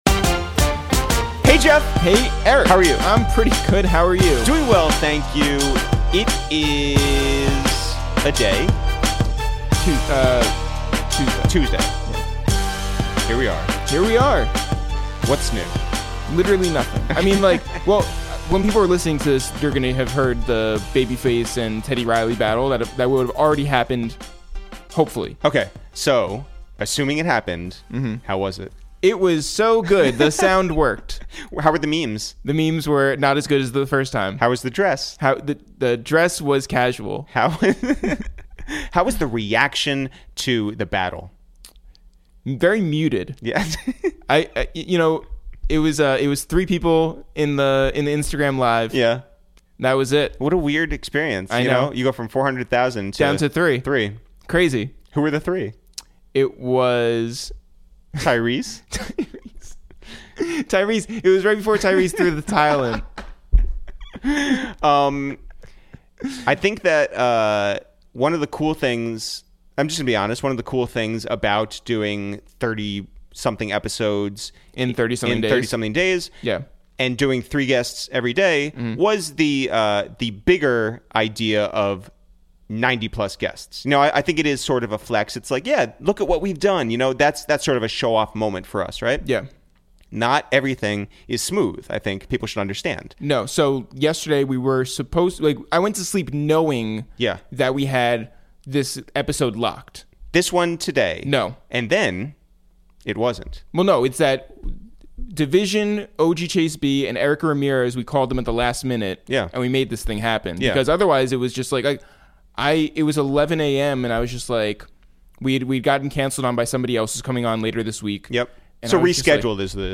Today on Episode 32 of Quarantine Radio, we make calls from our Upper West Side apartment to check in on genius R&B duo dvsn (singer Daniel Daley and producer Nineteen85) about their new album A Muse in Her Feelings, growing up in Jamaican households in Toronto, what it felt l...